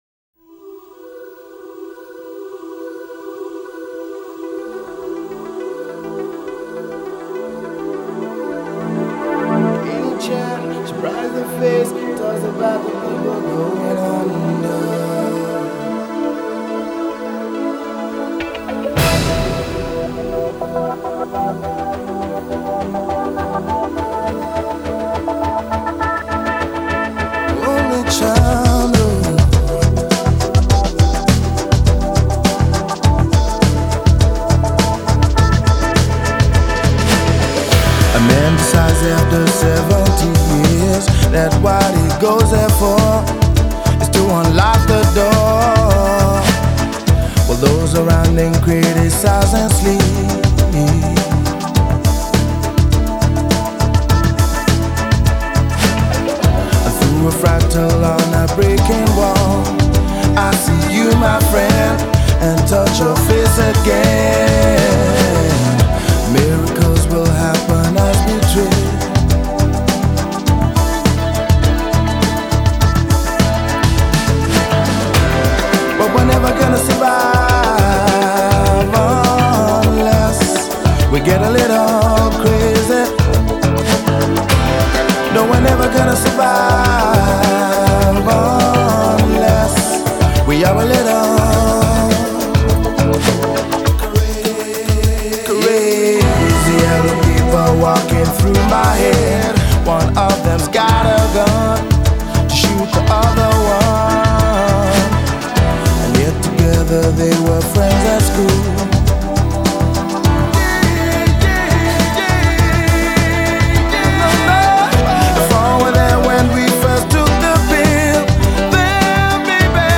خواننده موسیقی پاپ انگلیسی نیجریه‌ای تبار است.